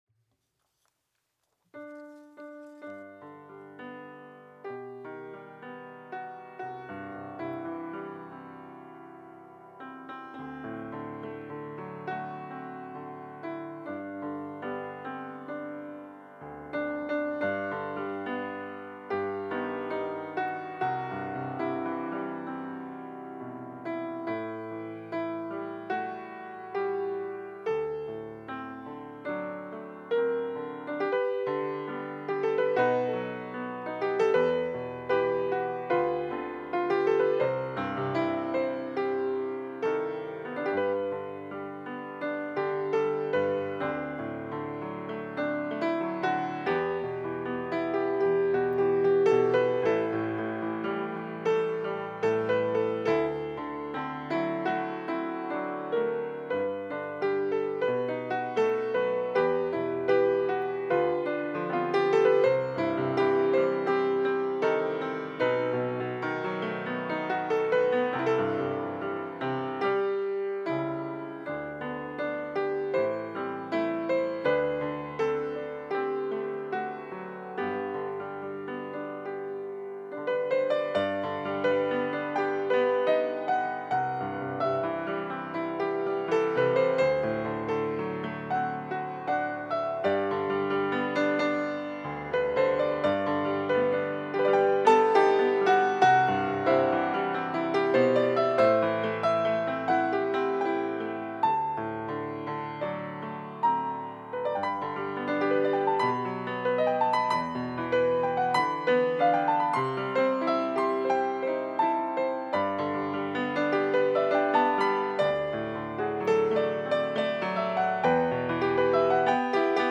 특송과 특주 - 십자가를 질 수 있나